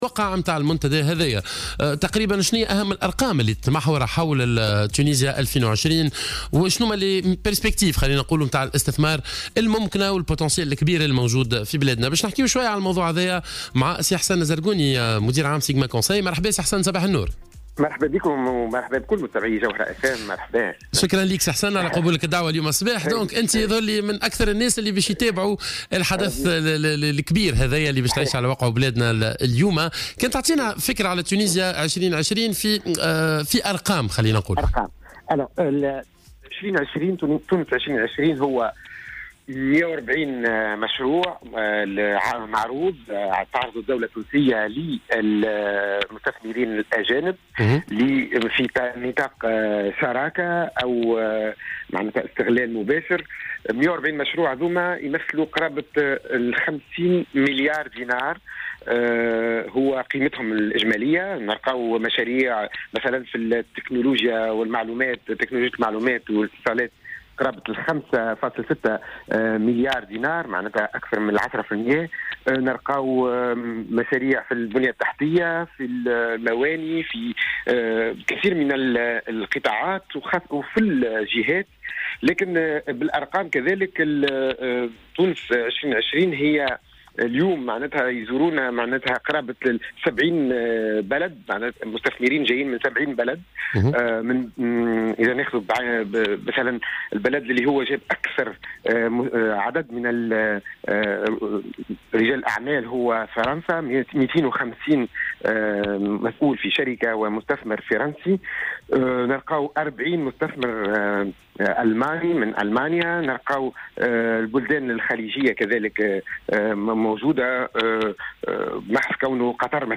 ce mardi 29 novembre sur les ondes de Jawhara FM, sur la portée de la tenue de la conférence internationale sur l'investissement en Tunisie.